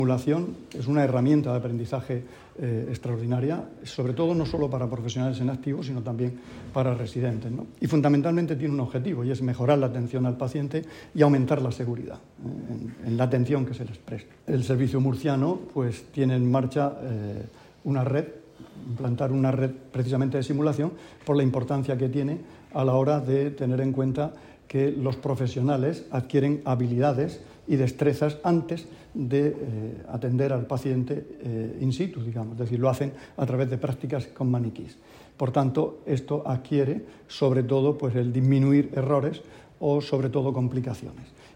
Sonido/ Declaraciones del consejero de Salud sobre el aula de simulación clínica del hospital Morales Meseguer.